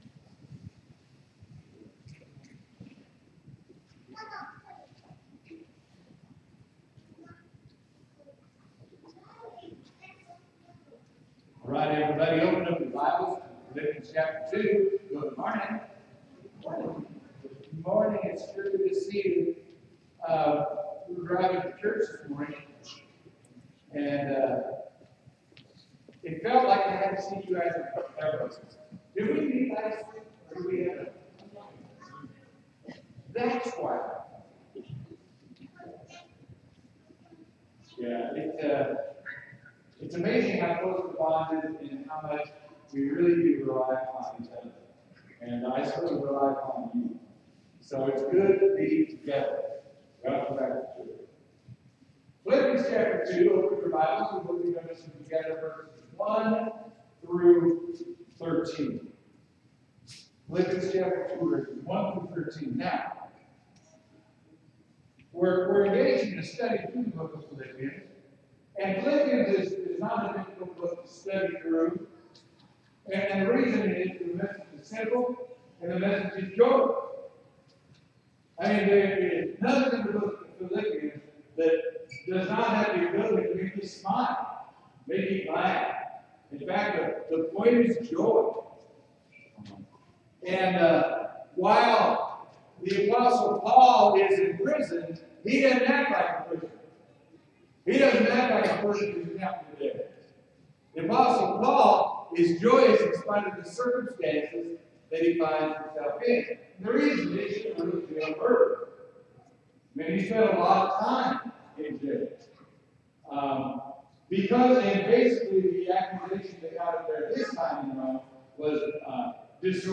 Sermon: Philippians 2:1-13